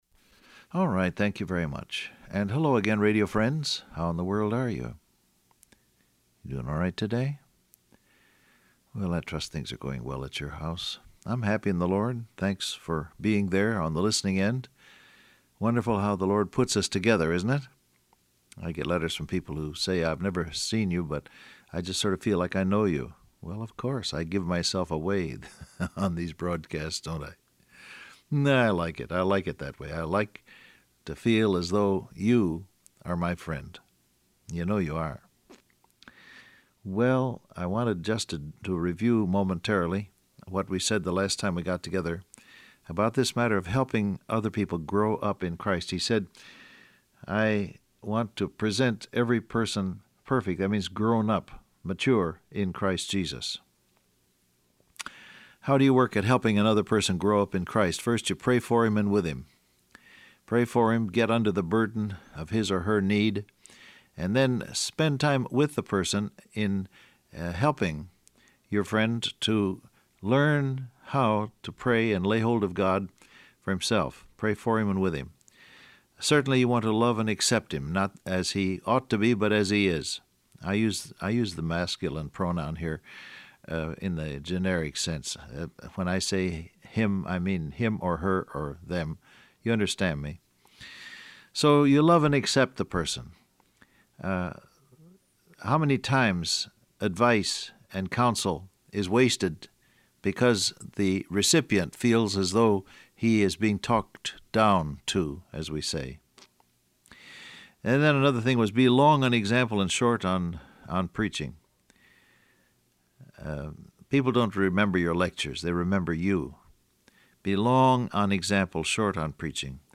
Download Audio Print Broadcast #1880 Scripture: Colossians 2:1-2 Transcript Facebook Twitter WhatsApp Alright, thank you very much.